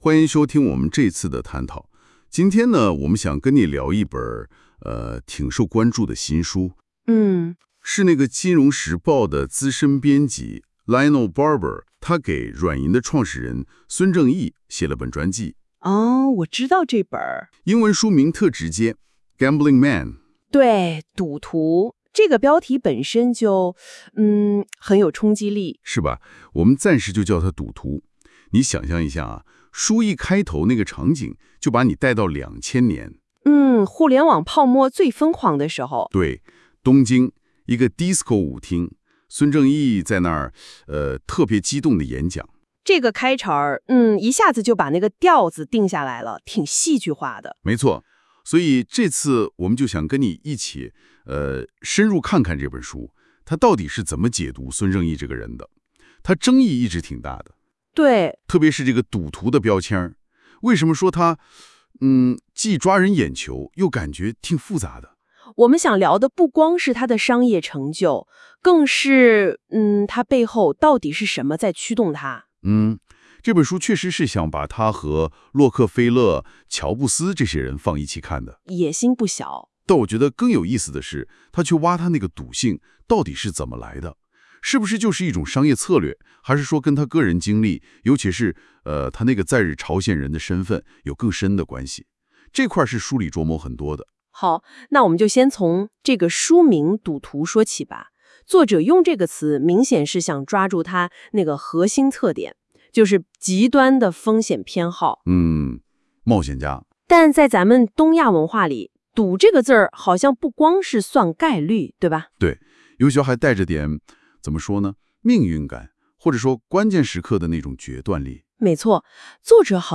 • 使用NotebookLM生成对话： 上周 Deep Reading 书评就使用Notebook LM 生成了一段对话 (